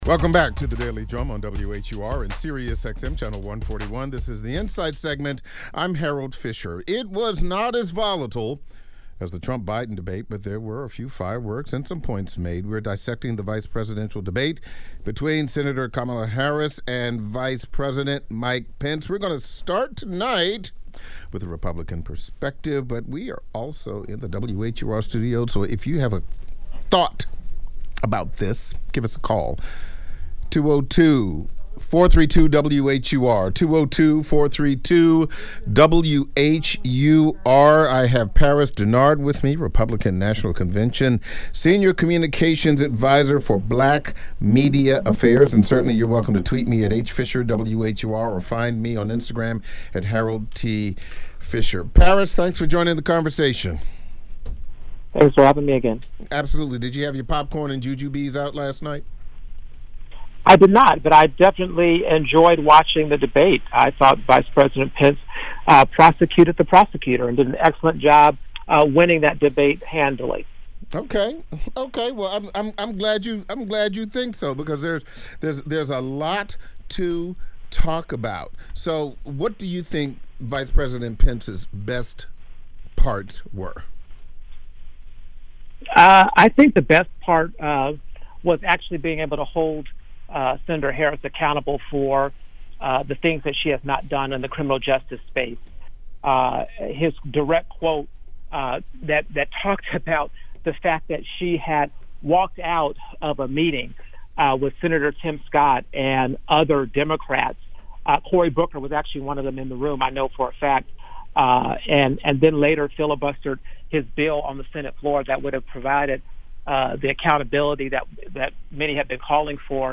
Analysis from the Democratic and Republican perspective.